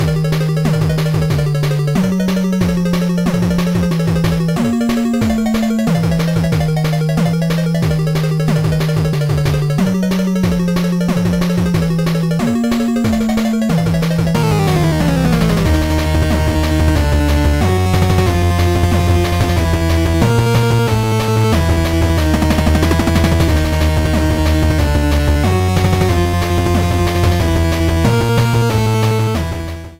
Started partway through the track and fadeout